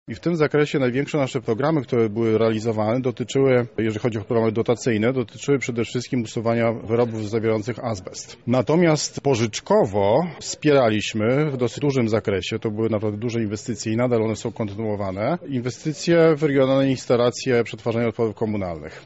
Podczas wczorajszej konferencji przedstawiono inwestycje, które udało się już zrealizować, a także propozycje projektów planowanych na przyszłość.
– mówi Paweł Gilowski, Prezes Wojewódzkiego Funduszu Ochrony Środowiska i Gospodarki Wodnej w Lublinie: